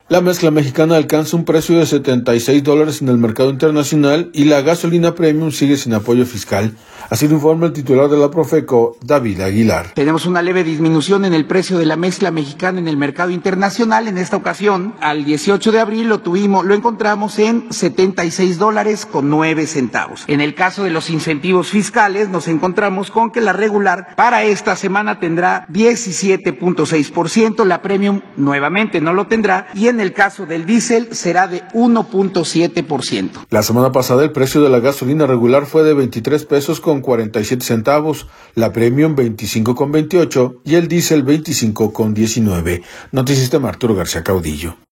La mezcla mexicana alcanza un precio de 76 dólares en el mercado internacional y la gasolina Premium sigue sin apoyo fiscal, así lo informa el titular de la Profeco, David Aguilar.